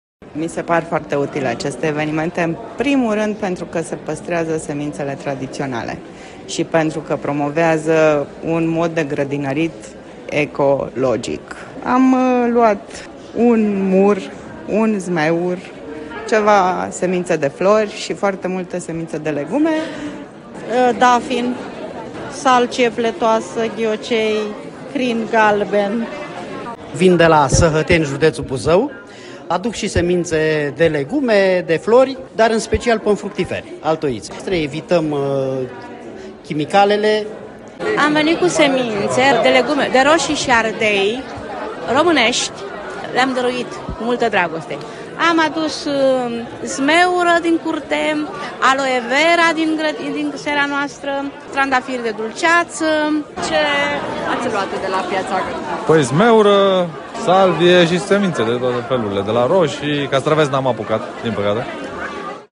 VOXURI-SEMINTE.mp3